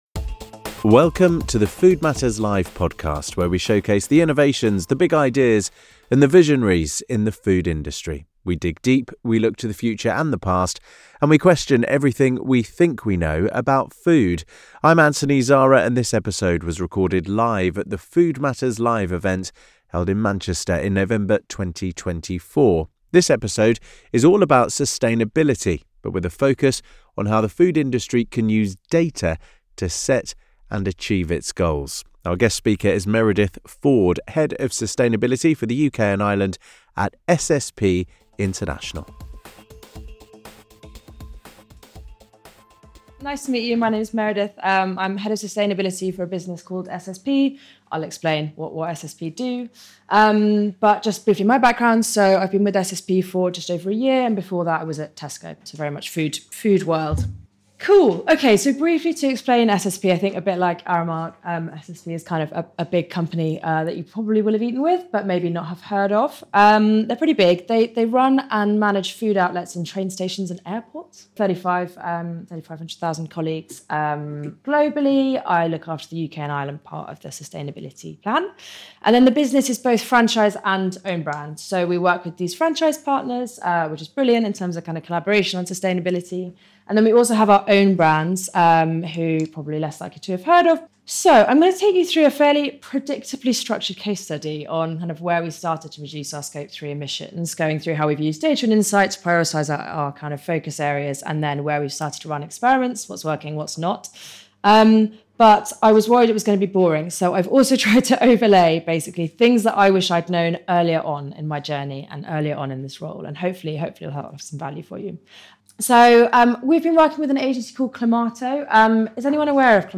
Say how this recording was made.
In this episode of the Food Matters Live podcast, recorded live at our event held in Manchester in November 2024, we hear from a leading figure about their journey with data and sustainability.